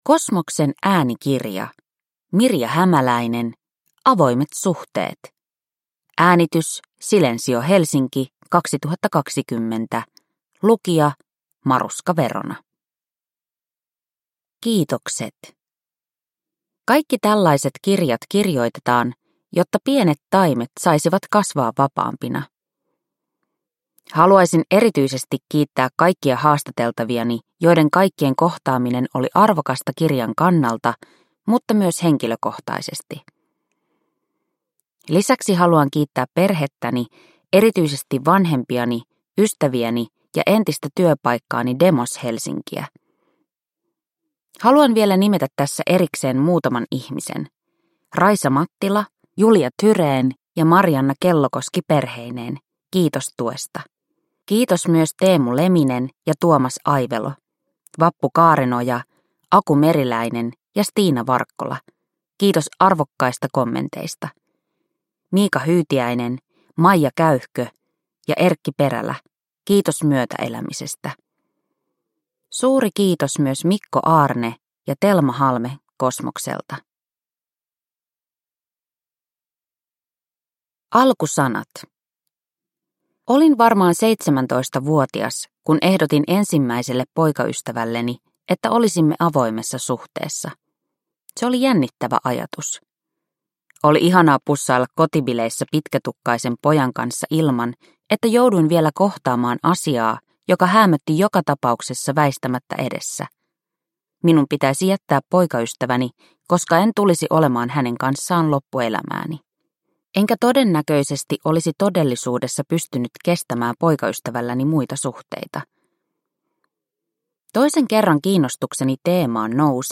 Avoimet suhteet – Ljudbok – Laddas ner